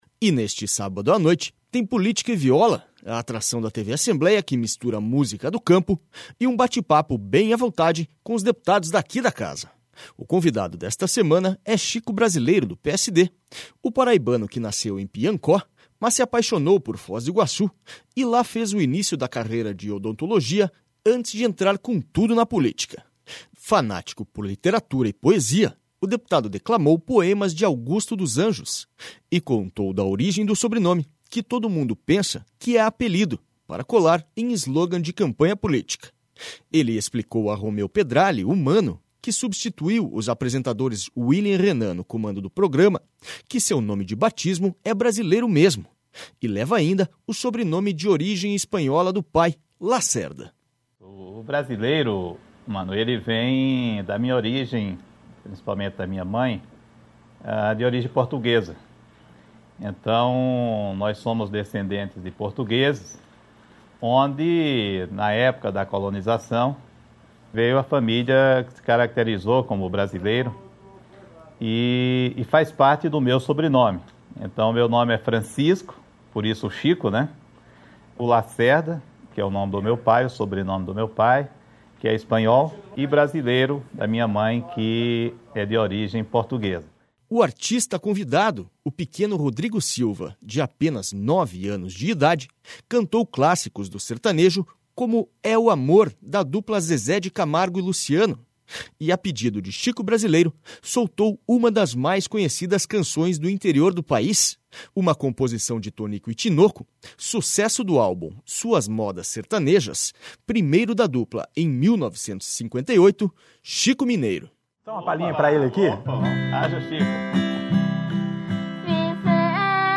Chico Brasileiro declama Augusto dos Anjos no Política e Viola deste sábado
E neste sábado à noite tem Política e Viola, a atração da TV Assembleia que mistura música do campo e um bate papo bem à vontade com os deputados daqui da casa.// O convidado desta semana é Chico Brasileiro, do PSD, o paraibano que nasceu em Piancó, mas se apaixonou por Foz do Iguaçu e lá fez o in...